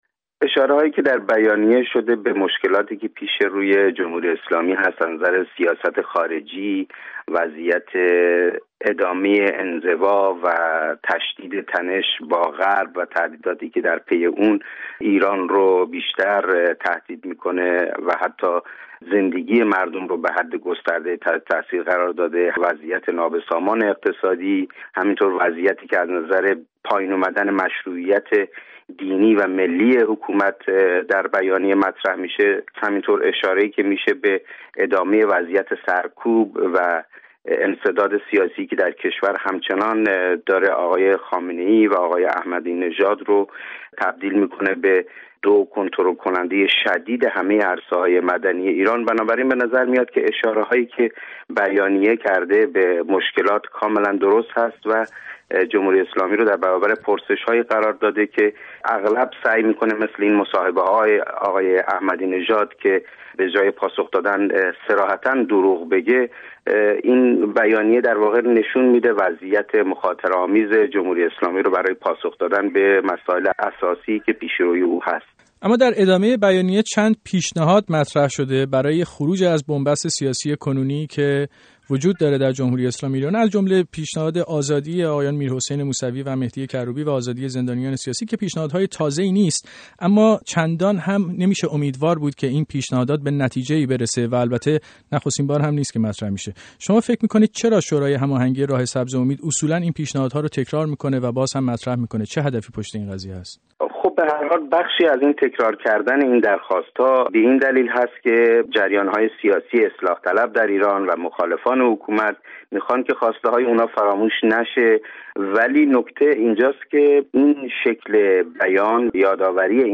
گفت و گو با علی اصغر رمضانپور درباره بیانیه دوم انتخاباتی شورای هماهنگی راه سبز امید